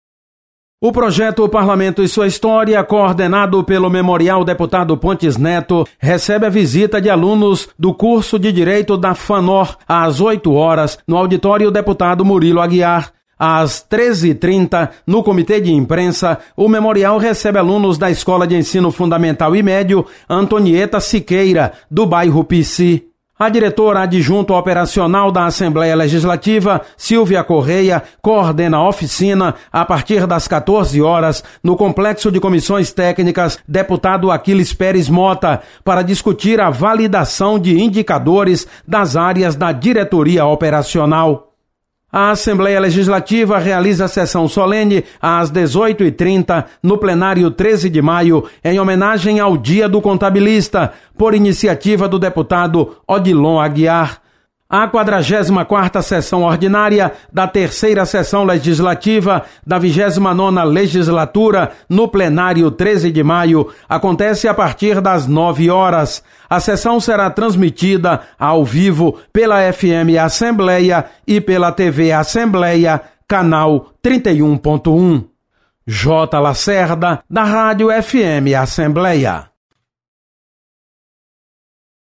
Acompanhe as informações das atividades desta terça-feira (02/05) na Assembleia Legislativa. Repórter